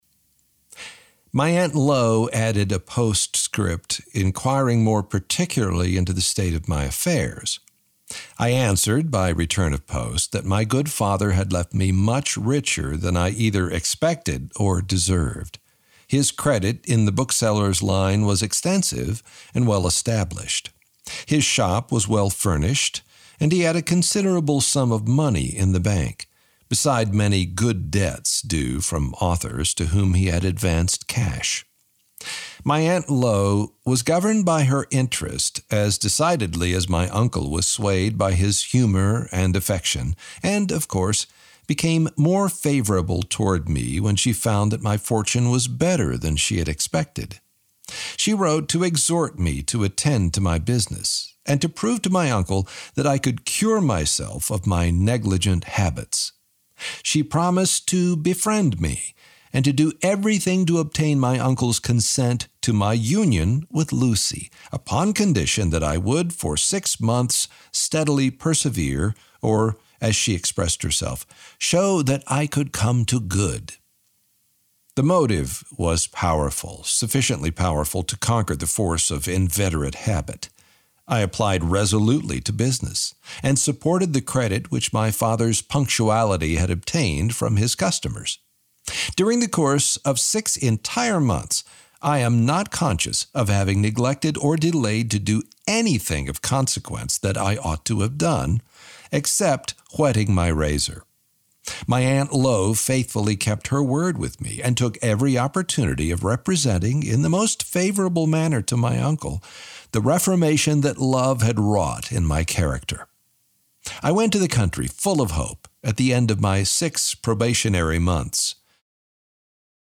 Audiobook: Tomorrow – MP3 download
This is an audiobook, not a Lamplighter Theatre drama.
Tomorrow-Audiobook-Sample.mp3